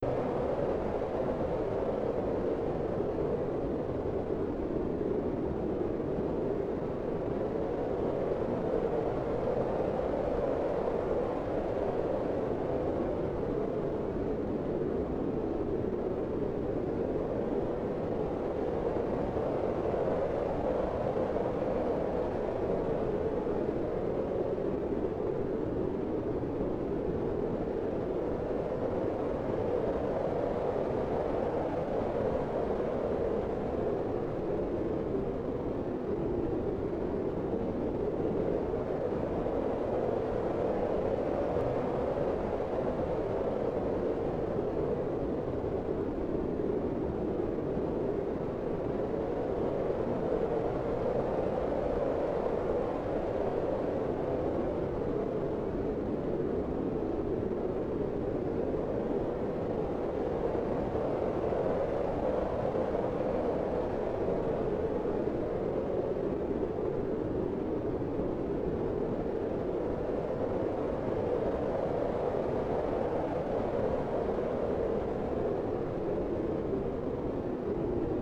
Wind in Garden
SoftWind2.wav